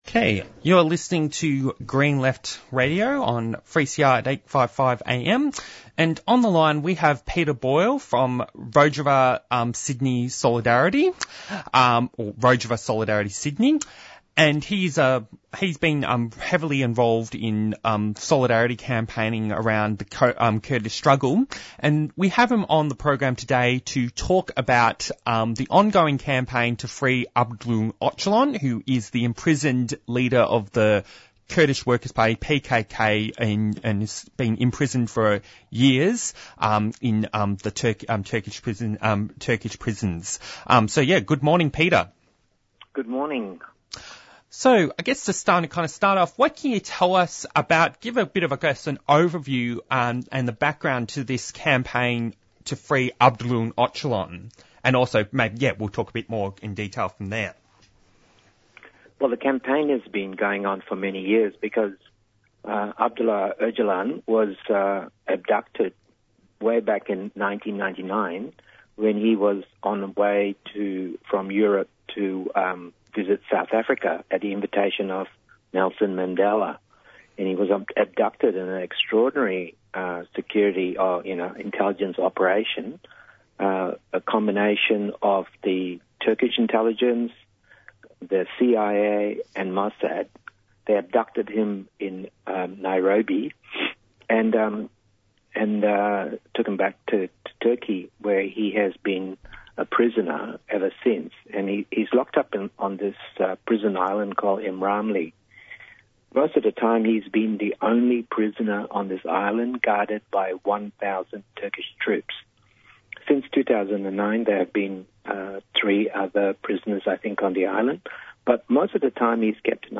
Interviews and Discussion